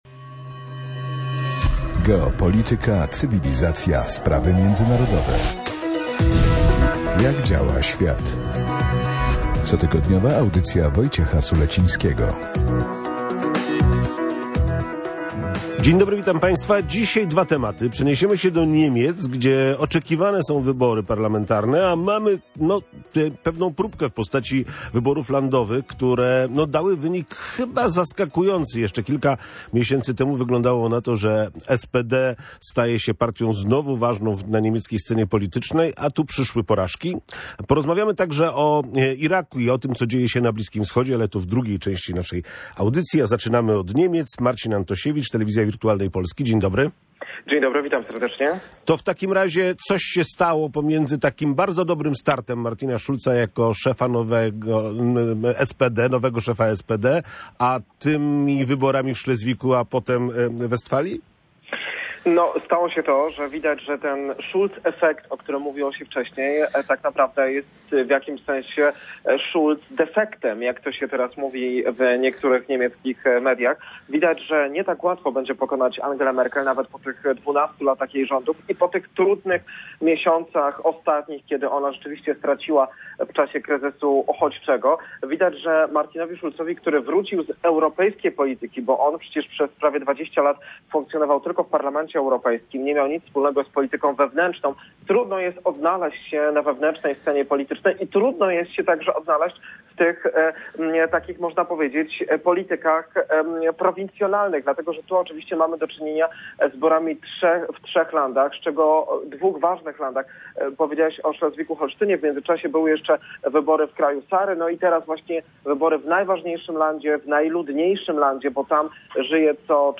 O niemieckiej polityce rozmawiali goście audycji Jak Działa Świat.